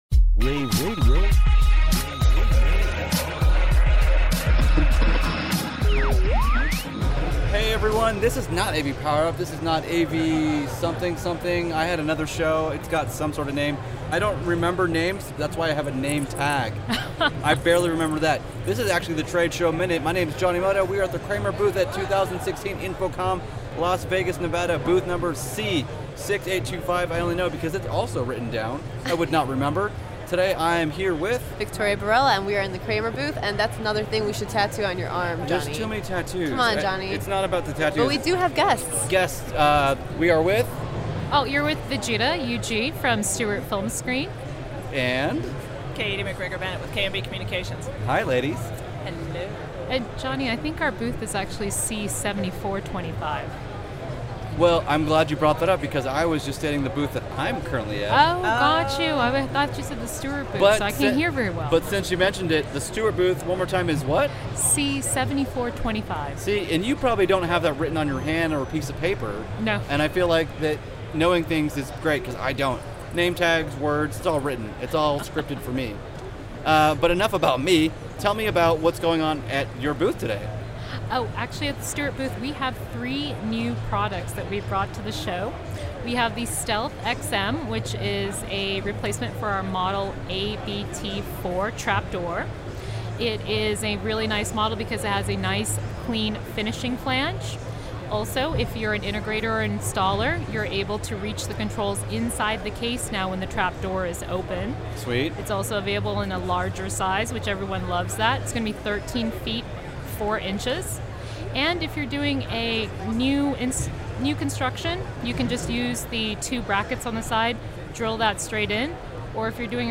at Official Day 1 at InfoComm 2016.